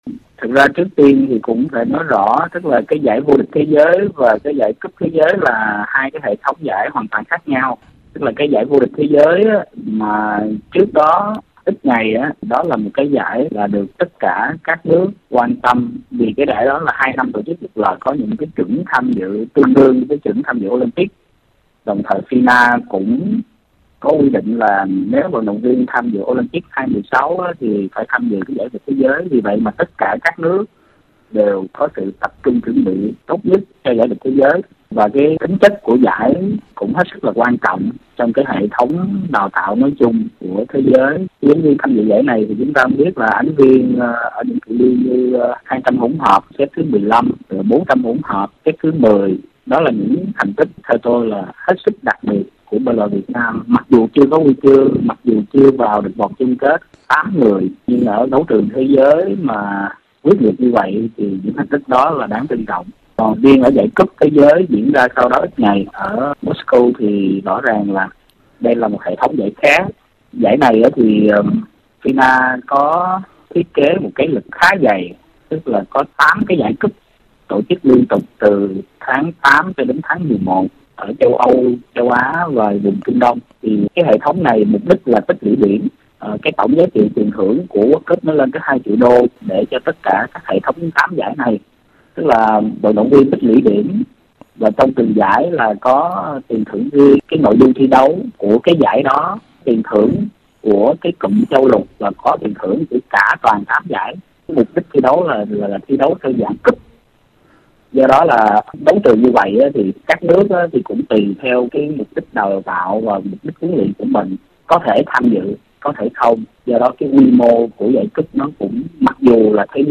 Trả lời phỏng vấn tạp chí thể thao của RFI